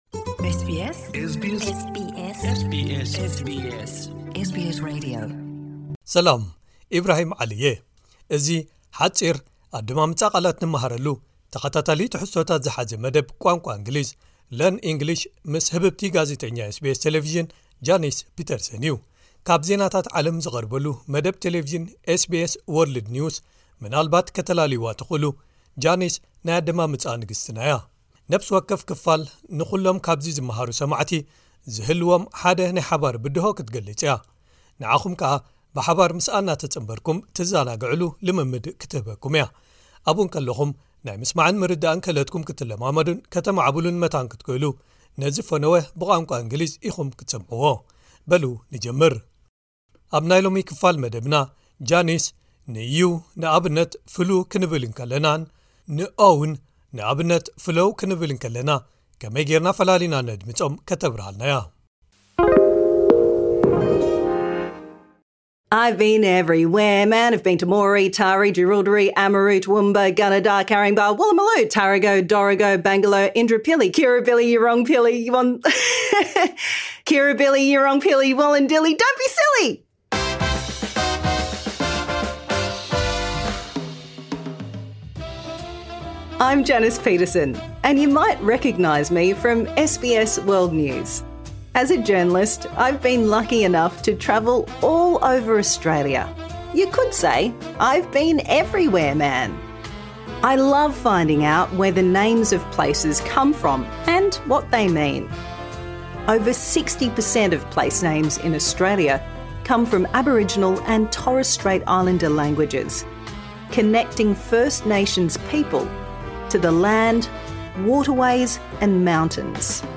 Learning objectives:Can pronounce /uː/ and /əʊ/ (flu and flow)
* Combines multiple verses of the song * To ensure all place names derive from First Australian languages, ‘Bendigo’ has been replaced by ‘Tarego’ and ‘Bambaroo’ has been replaced by ‘Amaroo’ Minimal Pairs: /uː/ suit blue flu glue oops goo (kangaroo, cooee, coolamon, woomera) /əʊ/ so bloke flow glow open go.